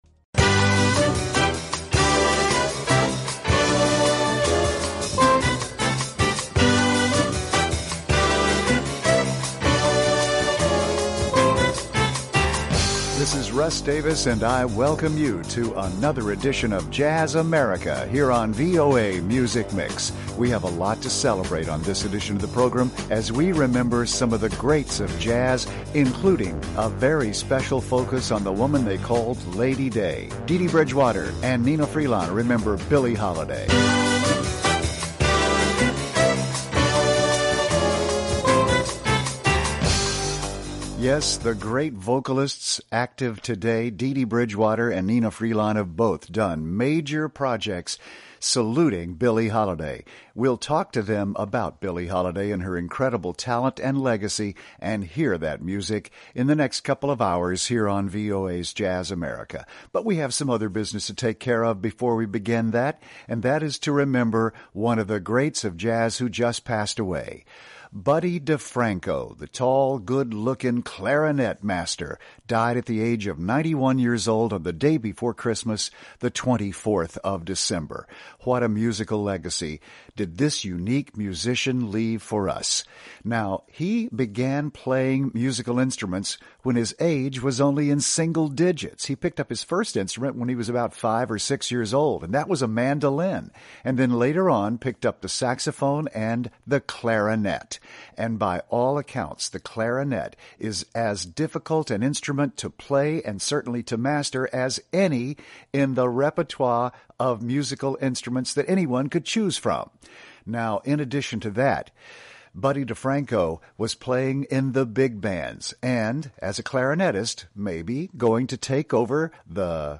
brings you the best in jazz, present and past.